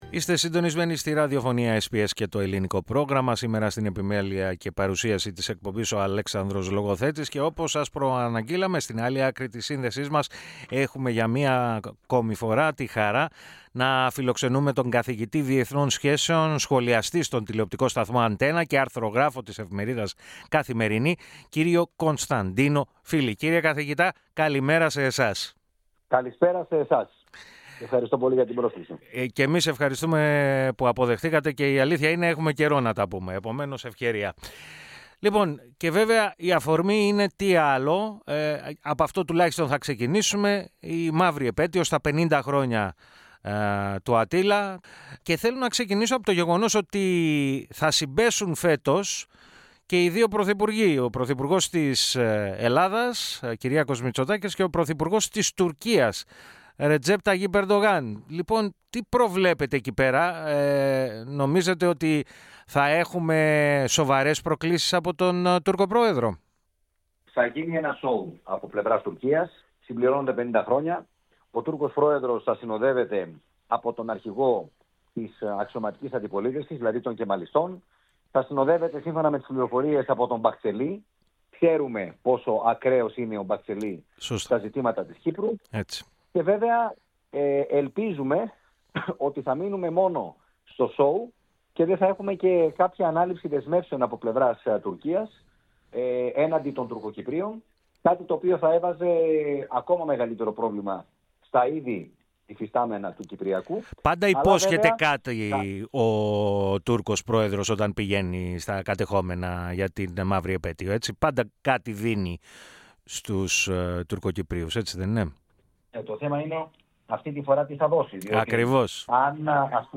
Για το Κυπριακό, αλλά, και για τις πολιτικές εξελίξεις στις ΗΠΑ, μίλησε στο Ελληνικό Πρόγραμμα της ραδιοφωνίας SBS, ο καθηγητής Διεθνών Σχέσεων,